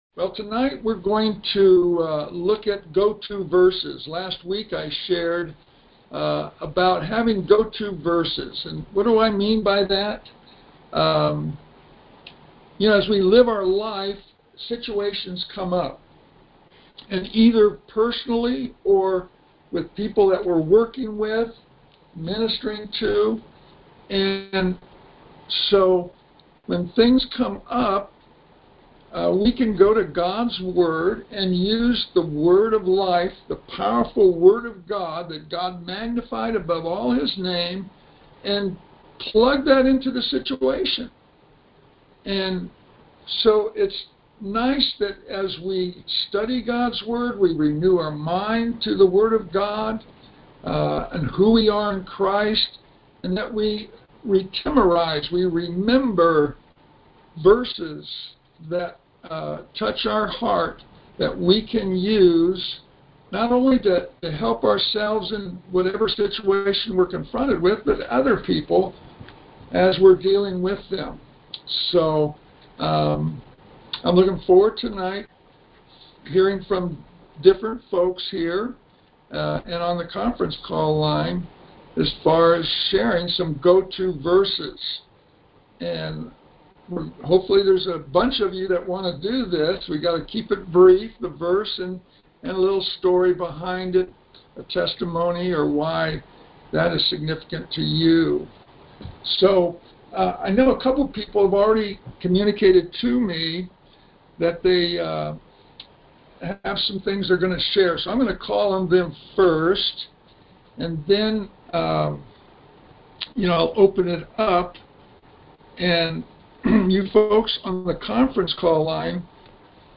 A number of saints share their go-to verse, along with how they have used them.